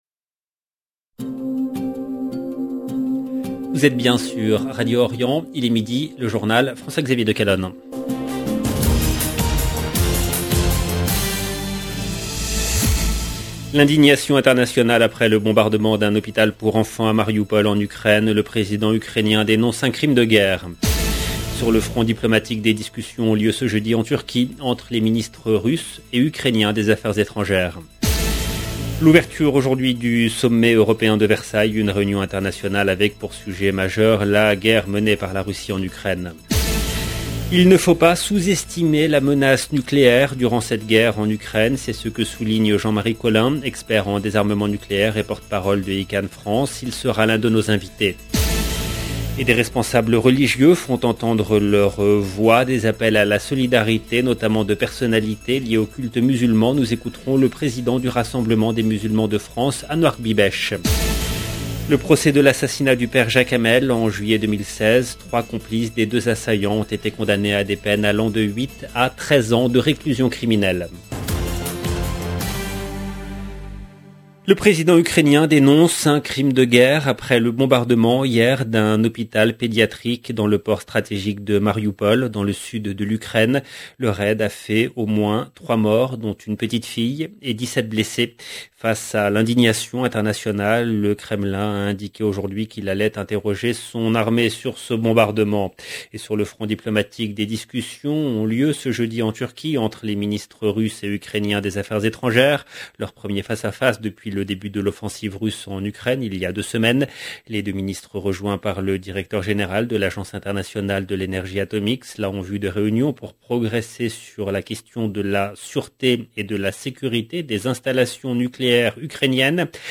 EDITION DU JOURNAL DE 12 H EN LANGUE FRANCAISE DU 10/3/2022